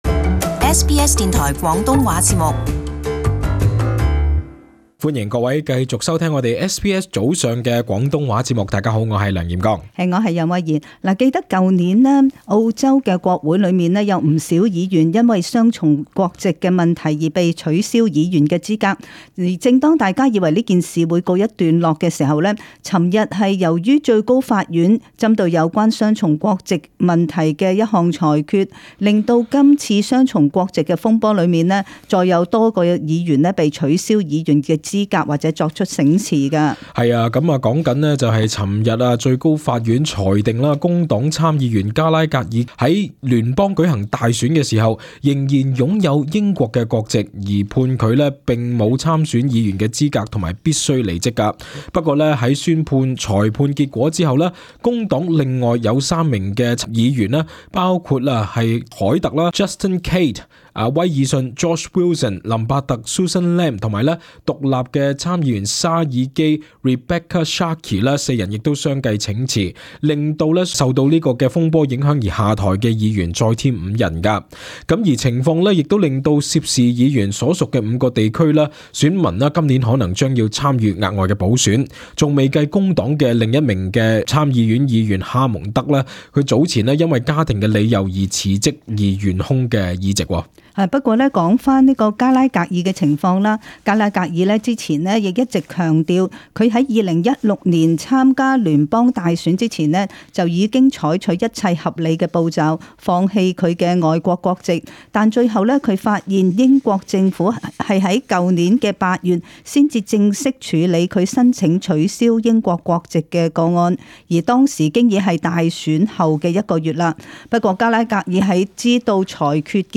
【時事報導】議員雙重國籍風波再有五人落台